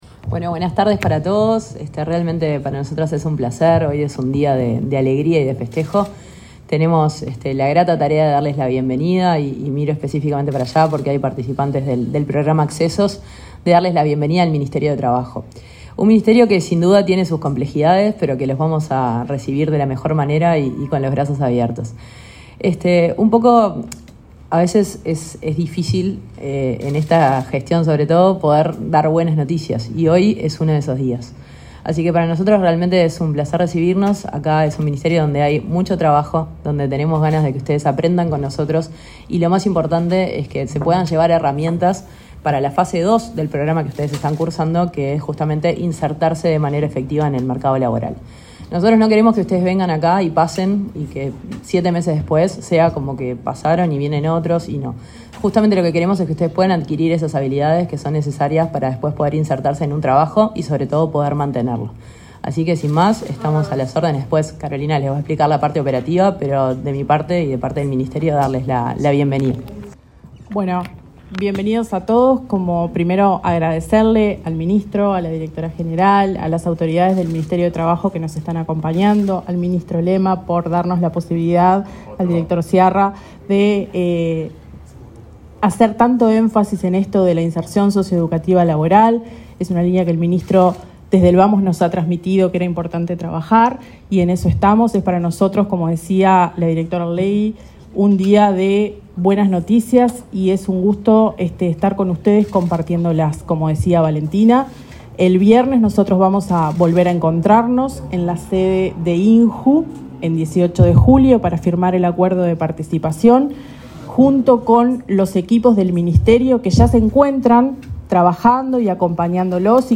Conferencia de prensa por el programa Accesos